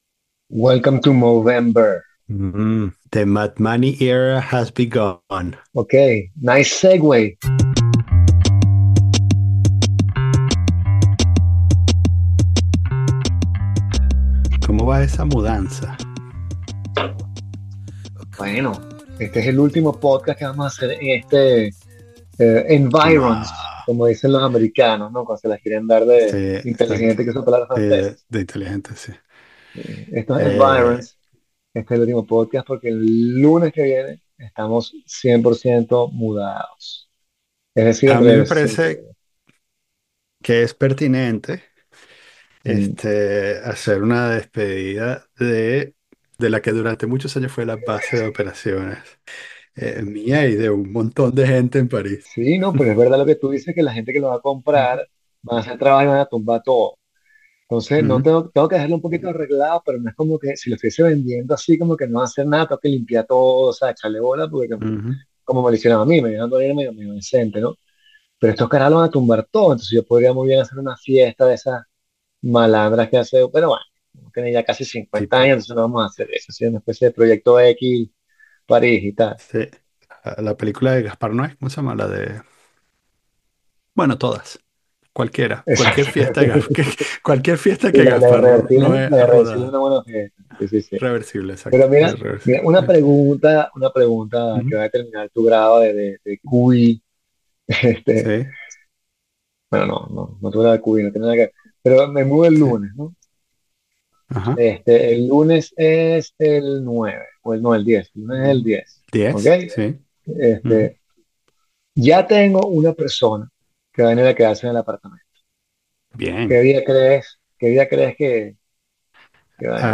El útlimo Podcast en el apartamento